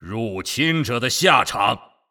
击杀语音